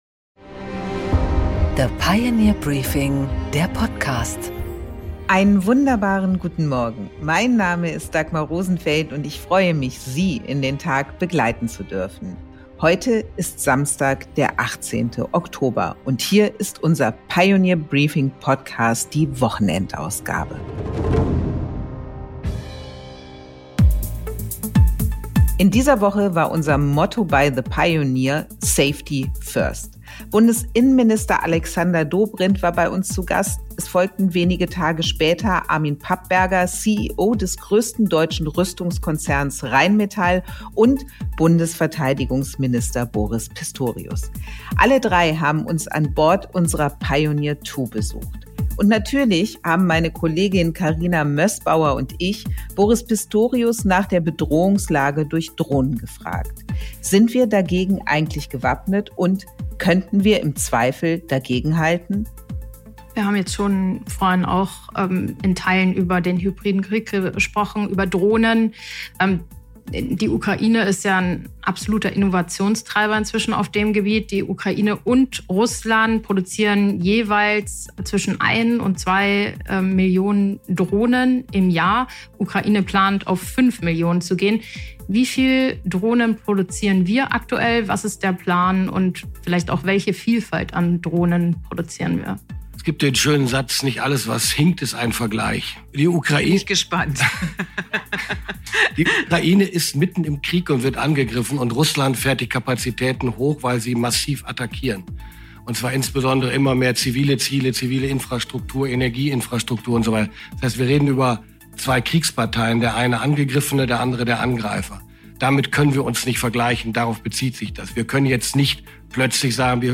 Zwei Defense-Insider haben nämlich unsere Pioneer Two zu Interview-Events vor Publikum besucht: Bundesverteidigungsminister Boris Pistorius (SPD) und Armin Papperger, CEO des größten deutschen Rüstungskonzern Rheinmetall.